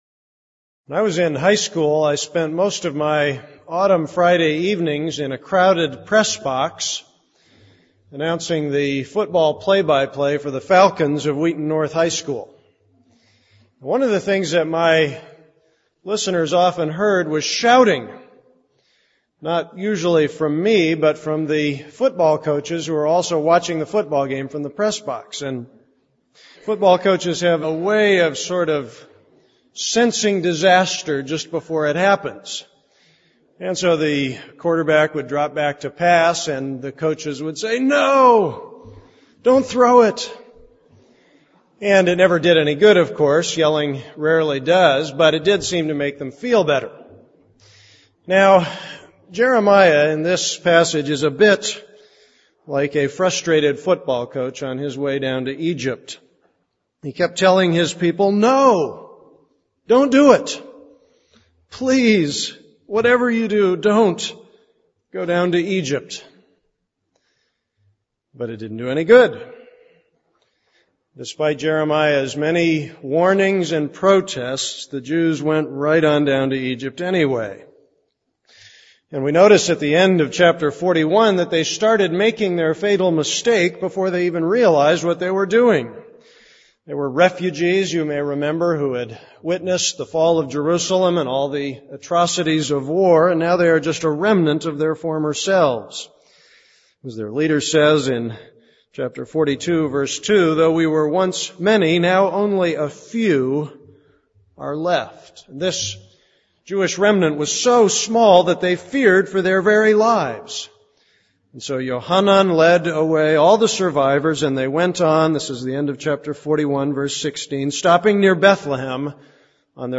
This is a sermon on Jeremiah 41:16-43:13.